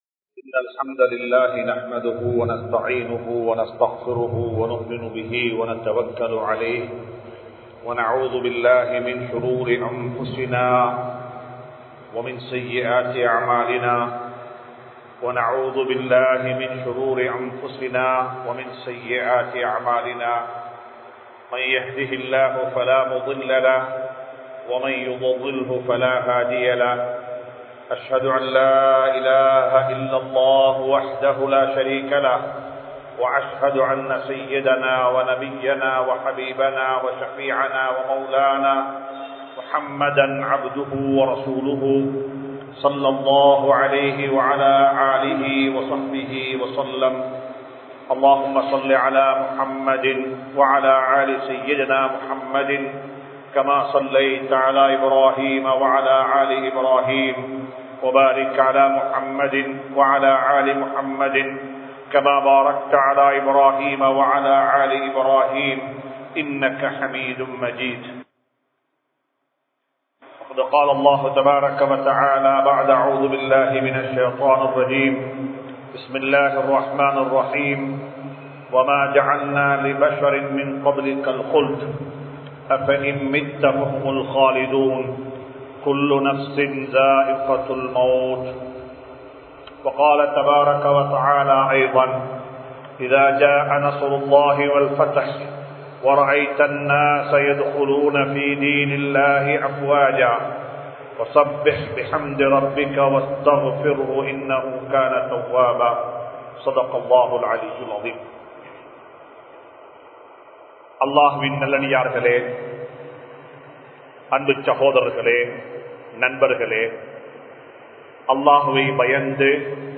Nabi(SAW)Avarhalin Mun Maathirihal (நபி(ஸல்)அவர்களின் முன்மாதிரிகள்) | Audio Bayans | All Ceylon Muslim Youth Community | Addalaichenai